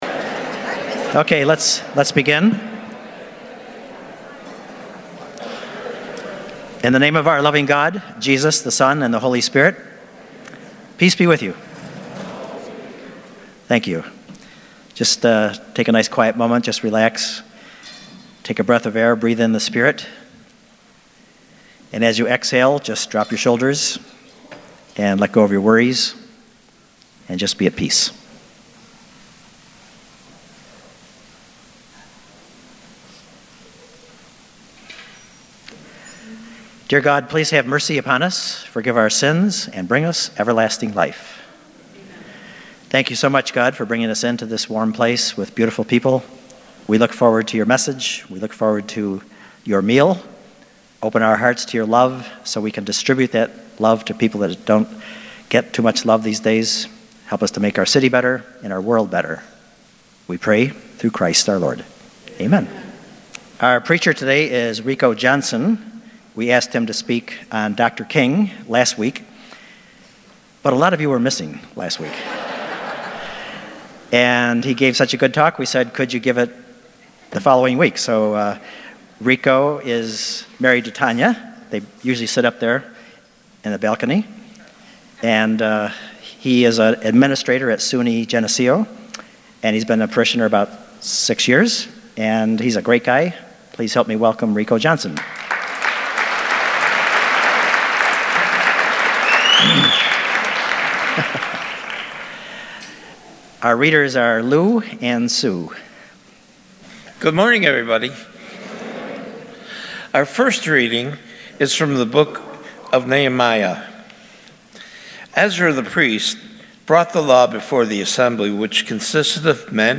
Spirtus-Christi-Mass-1.27.19.mp3